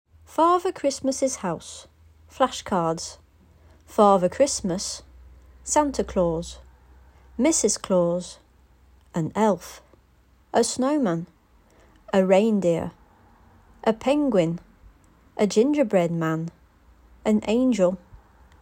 Des fichiers audio avec une voix anglaise native accompagnent l'ensemble conçu prioritairement pour le Cycle 3, mais avec des adaptations possibles pour le Cycle 2.